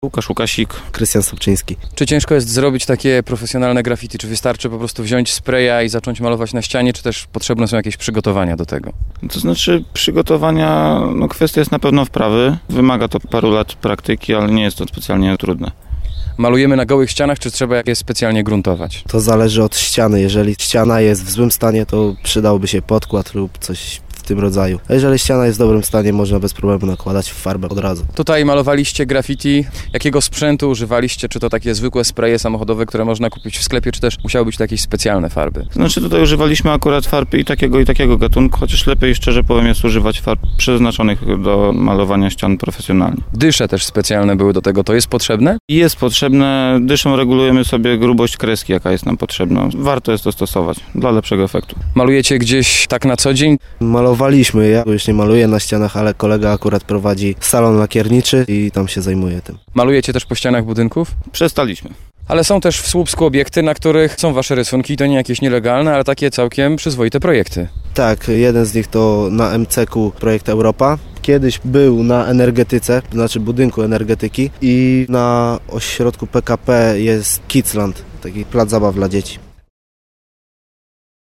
Graficiarze o swojej pracy - plik mp3